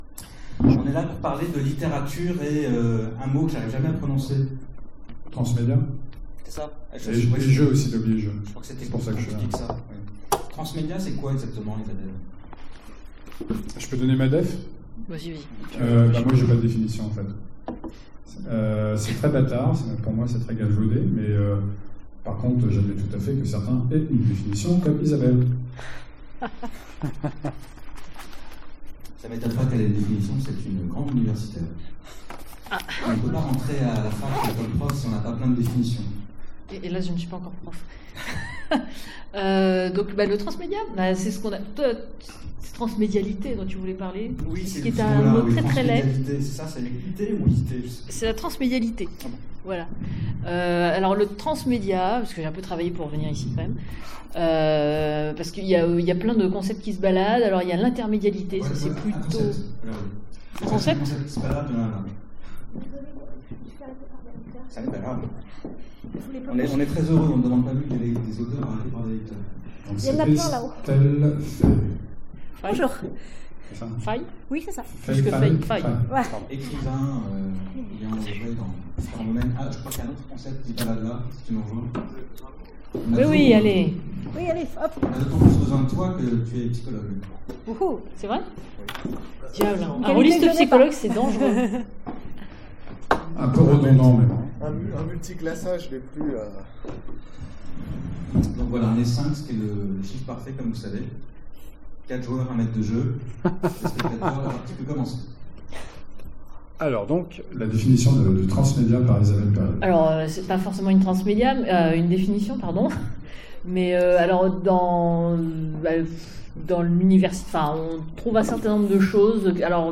FMI 2015 : Conférence Transmédia